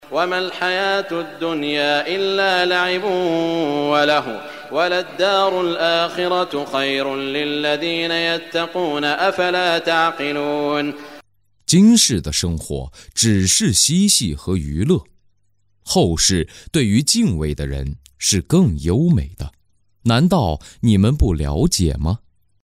中文语音诵读的《古兰经》第（艾奈尔姆）章经文译解（按节分段），并附有诵经家沙特·舒拉伊姆的朗诵